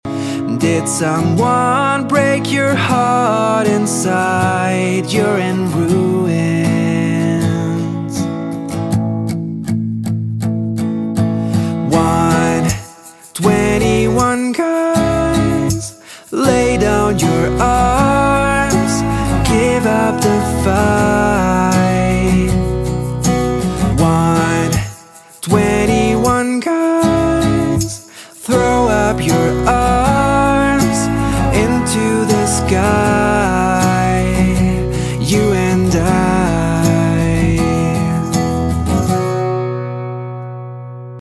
Here’s my acoustic version!